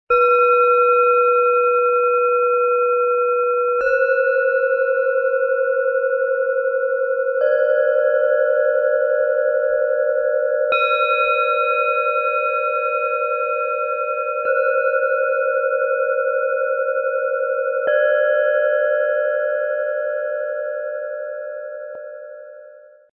Fokussiert, frei und voller Energie - 3 Planetenschalen für Deine Transformation (Ø 11,2 - 11,7 cm, 1,06 kg)
Mit höchster Präzision und Hingabe entsteht ein Unikat, das durch seinen klaren Klang und seine OM-Gravur zum Meisterwerk wird.
Tauchen Sie ein in die harmonischen Schwingungen von Lilith, DNA und Saturn.
Ein optimal abgestimmter Schlägel ist im Set enthalten – für klare und kraftvolle Klänge.
Tiefster Ton: Lilith
Bengalen Schale, Matt, 11,7 cm Durchmesser, 6,4 cm Höhe
Mittlerer Ton: DNA
Höchster Ton: Saturn